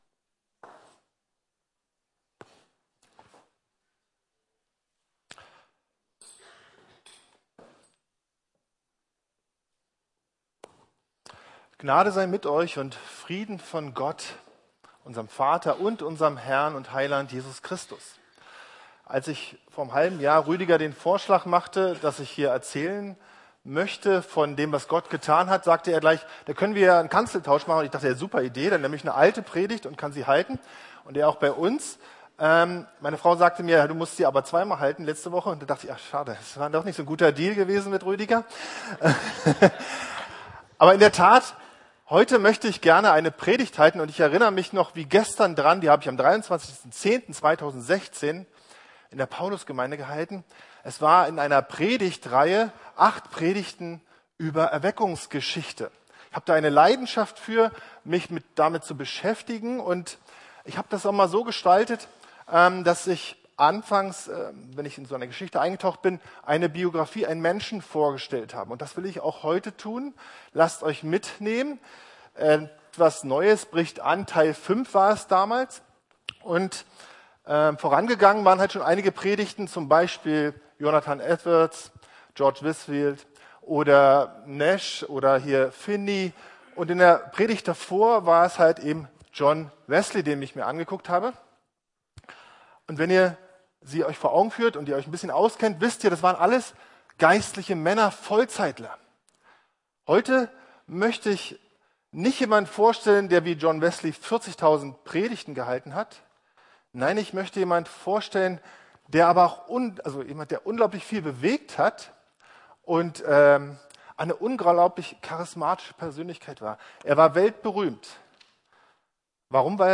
Wie kann die Gesellschaft durch das Evangelium transformiert werden? ~ Predigten der LUKAS GEMEINDE Podcast